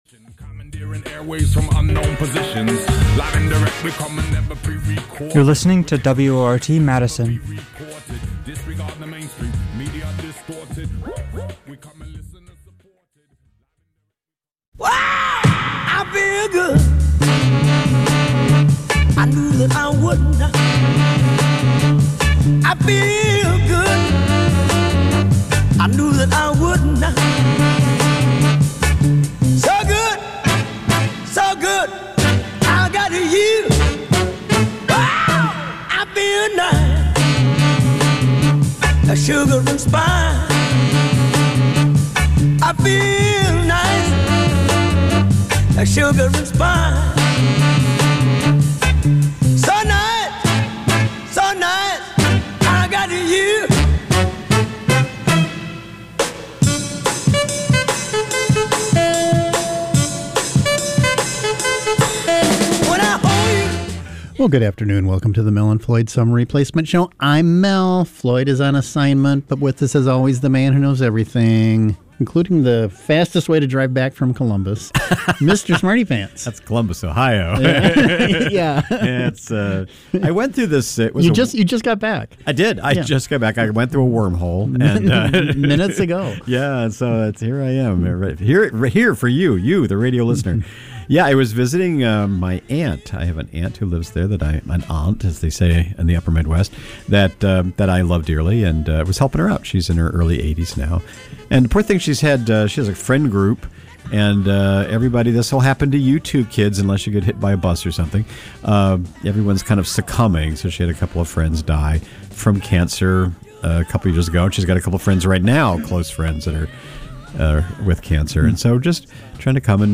Two wacky funny guys broadcast a hilarious blend of political commentary, weird news, and stand up comedy.